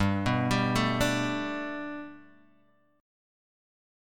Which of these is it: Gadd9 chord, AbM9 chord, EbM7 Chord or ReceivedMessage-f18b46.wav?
Gadd9 chord